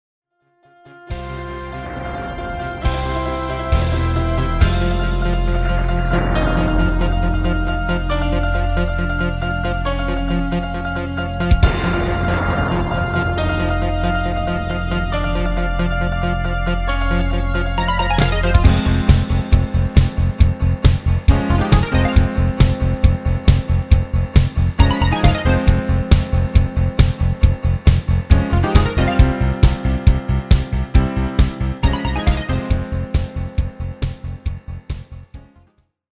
Demo/Koop midifile
Genre: Disco
- Géén vocal harmony tracks